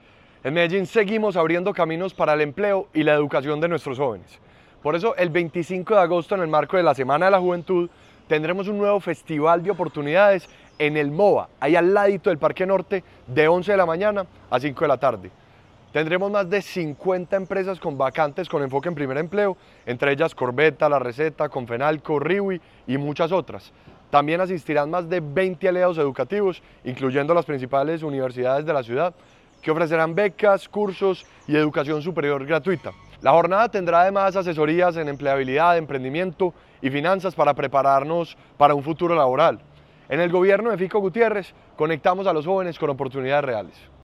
Declaraciones-del-secretario-de-la-Juventud-Ricardo-Jaramillo-Velez.mp3